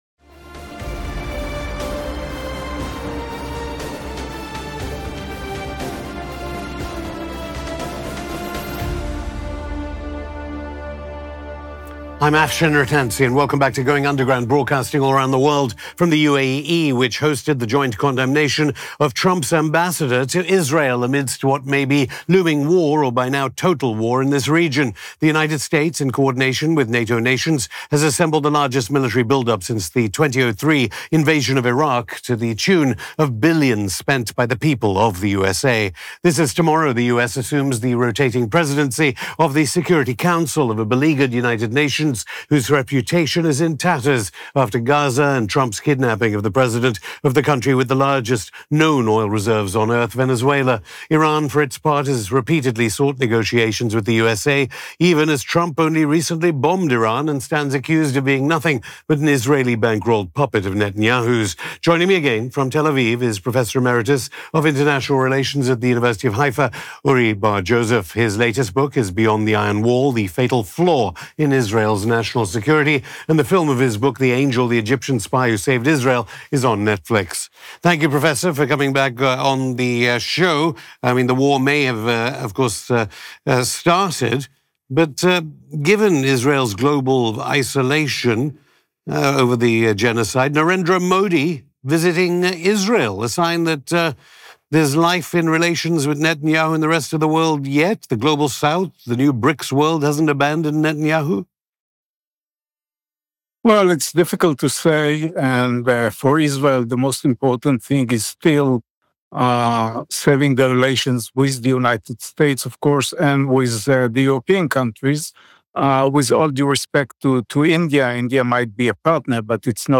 Going Underground Hosted by Afshin Rattansi US-ISRAELI WAR ON IRAN: Can Israel Survive Full-Scale Iranian Retaliation?